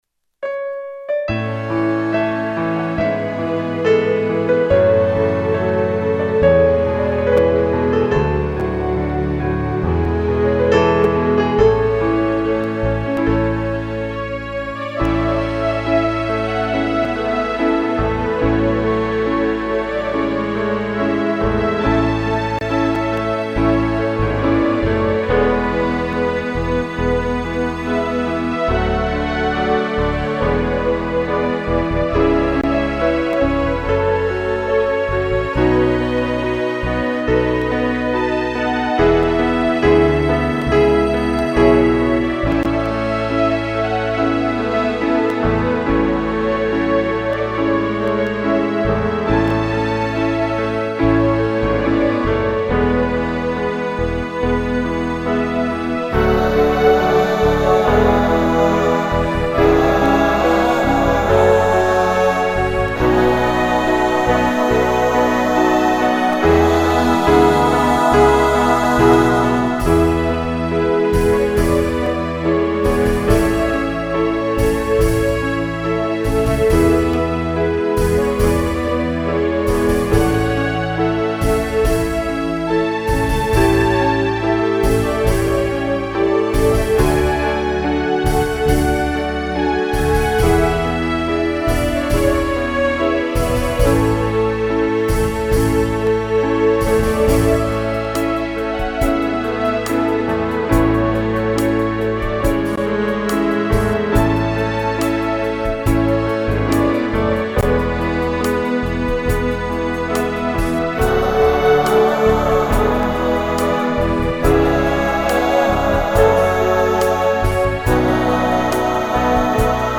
KFbp0gAQVy7_Himno-al-COlegio-CEI-Instrumental.mp3